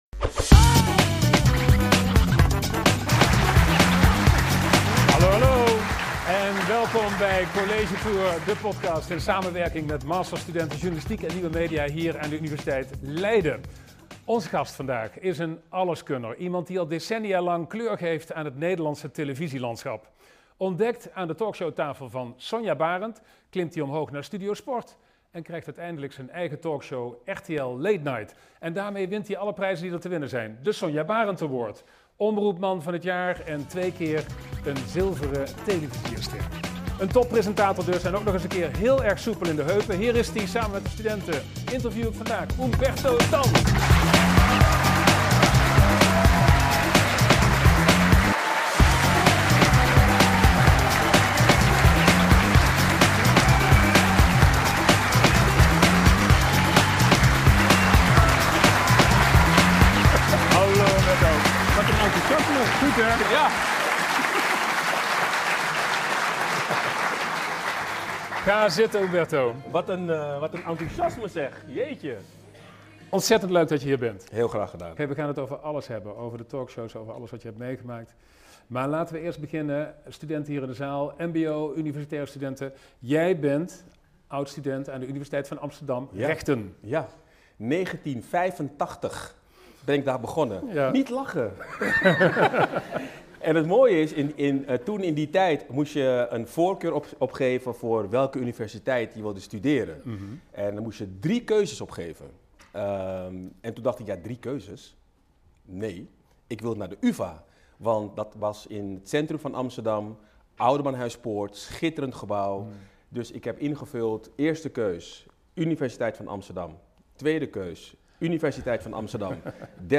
Hij vertelt openhartig hoe hij met dit grote verlies omgaat en hoe de voormalig sportverslaggever toch altijd het positieve uit het leven weet te halen. In College Tour de podcast! lukt het Humberto om het publiek te laten lachen, verwonderen en ook vooral te emotioneren.
🎥 Dit interview met Humberto Tan werd opgenomen in collegezaal Lipsius 0.11 aan de Universiteit Leiden op 10 december 2025.